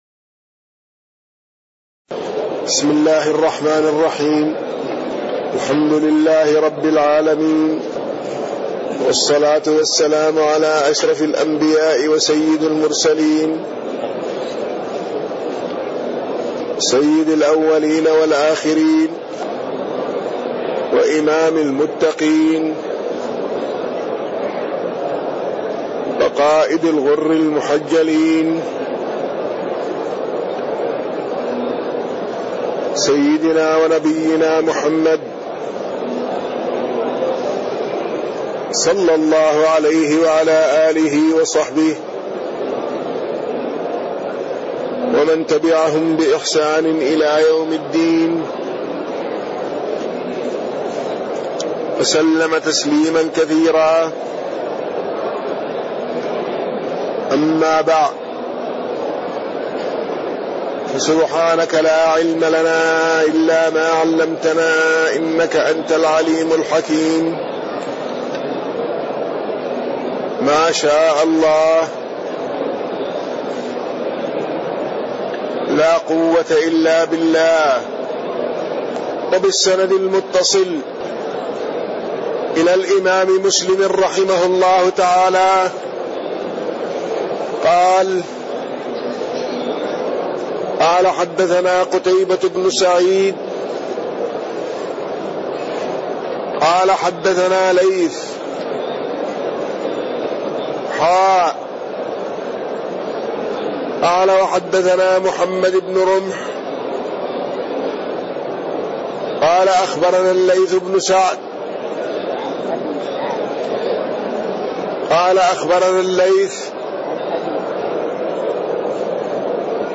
تاريخ النشر ١٣ جمادى الآخرة ١٤٣٢ هـ المكان: المسجد النبوي الشيخ